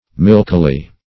milkily - definition of milkily - synonyms, pronunciation, spelling from Free Dictionary Search Result for " milkily" : The Collaborative International Dictionary of English v.0.48: Milkily \Milk"i*ly\, adv. In a milky manner.
milkily.mp3